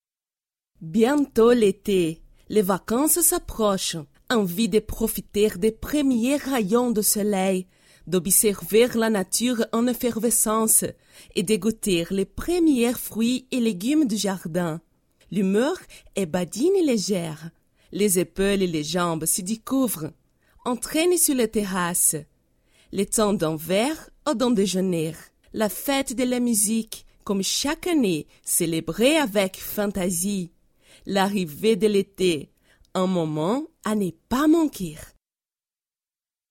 Je parle français avec un accent brésilien.
annonceuse brèsilienne, français avec accent brèsilien, home studio
Sprechprobe: Sonstiges (Muttersprache):